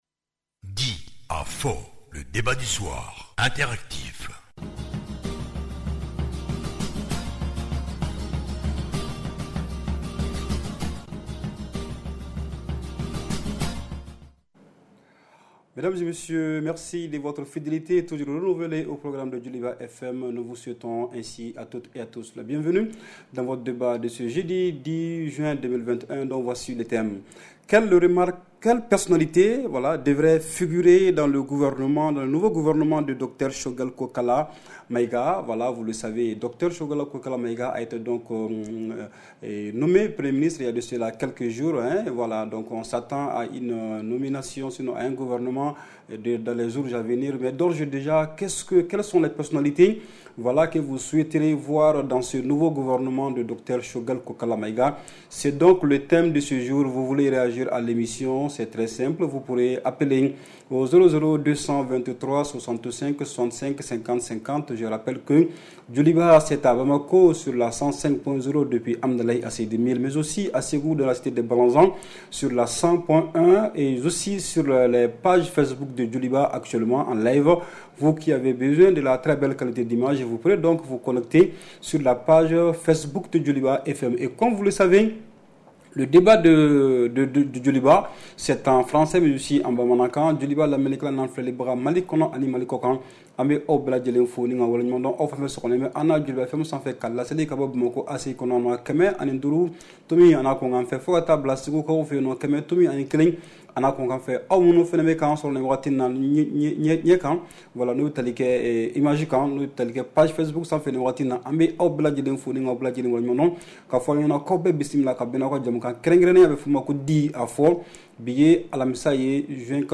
REPLAY 10/06 – « DIS ! » Le Débat Interactif du Soir